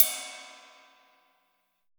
MTLRIDE EDG.wav